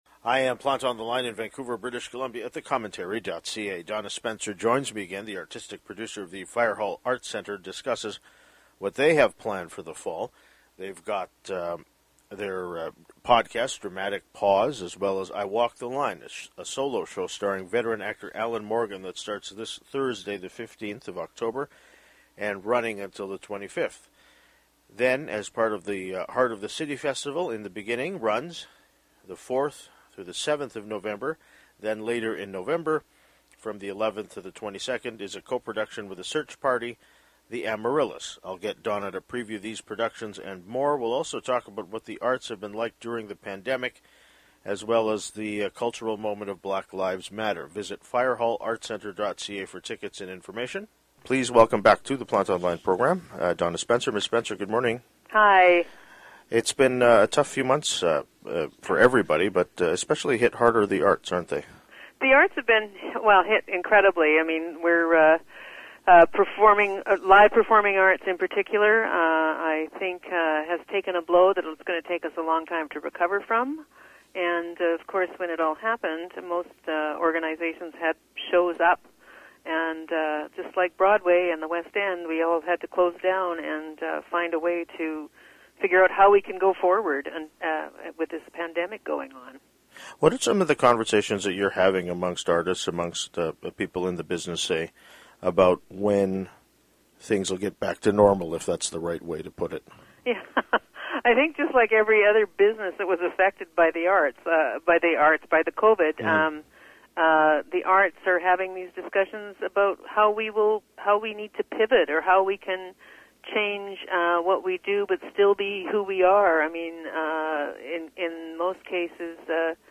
Text of introduction